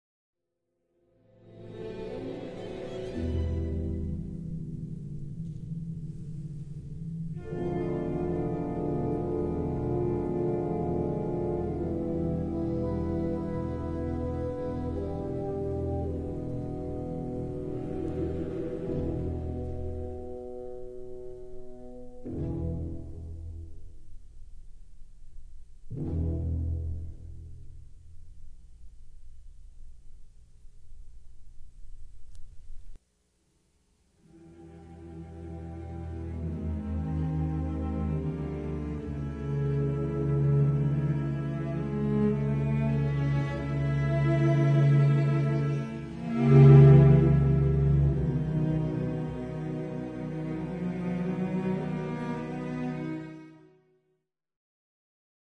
Don Juan is a brilliant orchestral showpiece, but it ends quite solemnly, as described here by Alex Ross in The Rest is Noise: "an upward-scuttling scale in the violins, a quiet drumroll, hollow chords on scattered instruments, three thumps, and silence."
It's an expectant silence to say the least because the music hardly seems resolved.
The lingering trill in the low strings that happens about 18 seconds into the Don Juan clip above immediately reminded me of something else, and I couldn't think what, which of course is insanely frustrating - like seeing the slightly familiar face of some actor and not being able to place it.